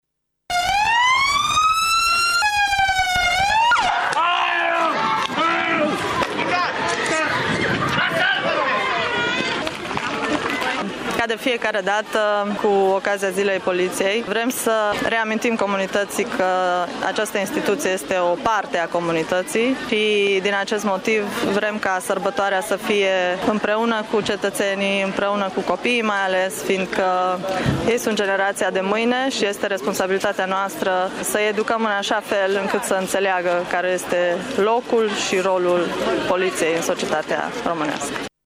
Inspectoratul Judeţean de Poliţie şi-a deschis porţile, astăzi, pentru câteva sute de prichindei din Tîrgu-Mureş cu ocazia Zilei Poliţiei Române.